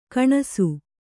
♪ kaṇasu